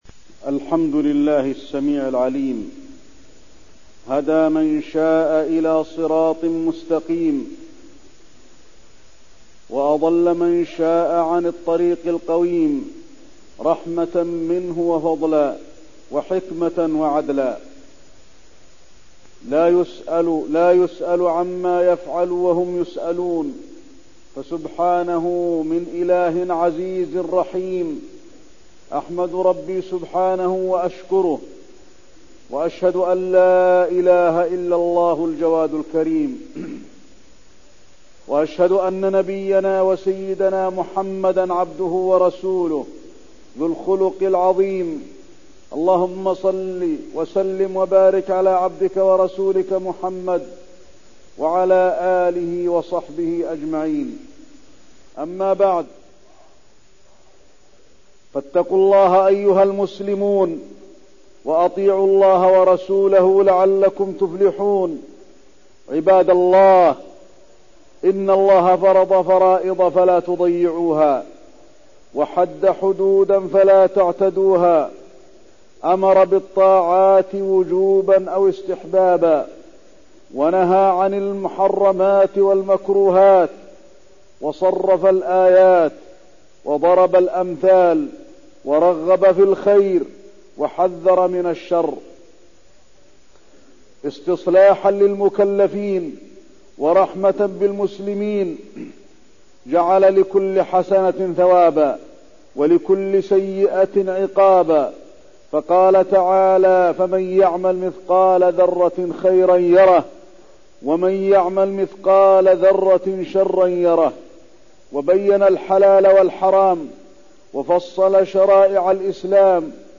تاريخ النشر ٨ رجب ١٤١٣ هـ المكان: المسجد النبوي الشيخ: فضيلة الشيخ د. علي بن عبدالرحمن الحذيفي فضيلة الشيخ د. علي بن عبدالرحمن الحذيفي آثار الطاعات وضرر المعاصي